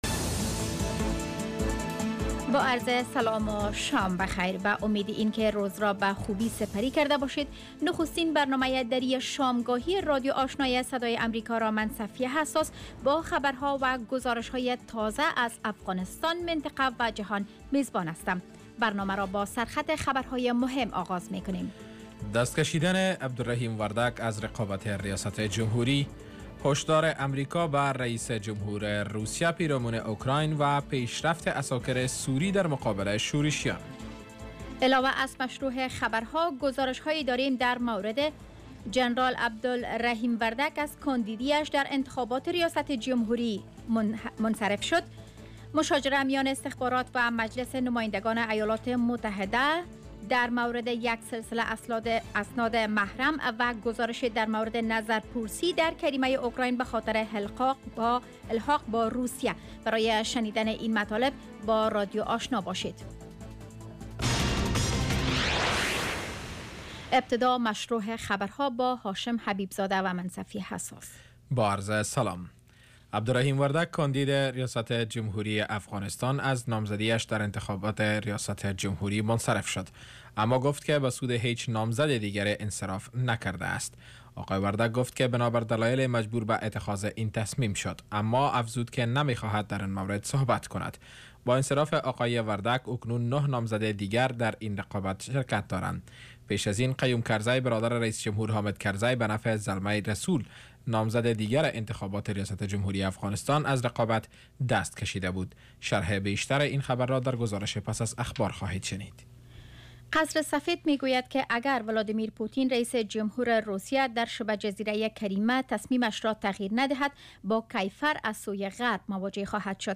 Radio evening first news half-hour show